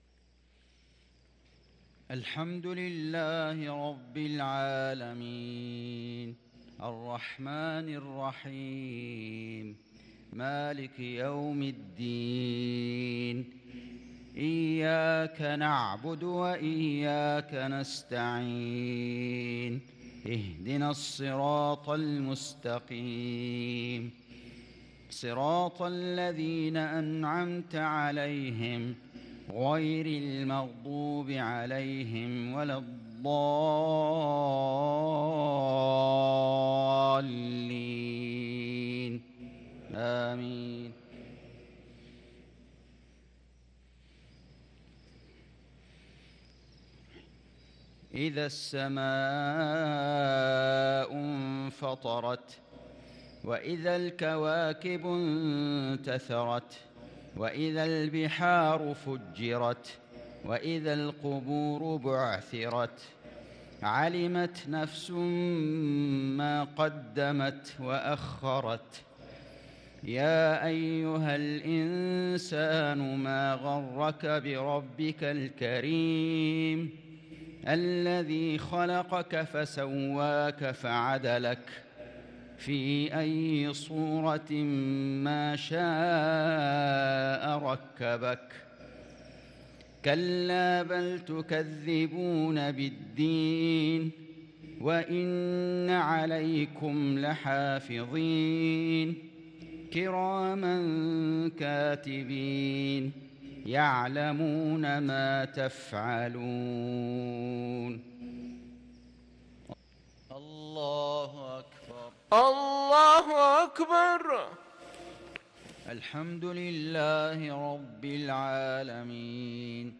صلاة المغرب للقارئ فيصل غزاوي 24 شعبان 1443 هـ